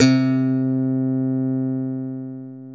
KW FUNK  C 3.wav